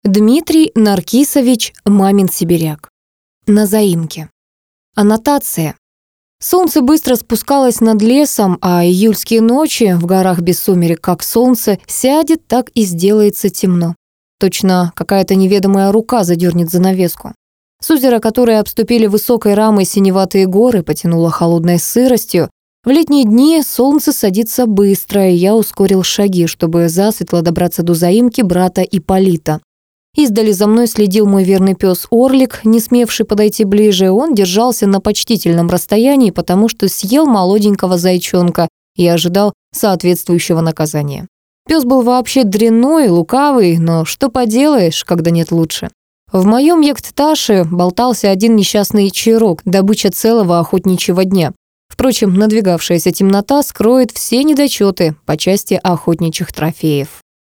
Аудиокнига На заимке | Библиотека аудиокниг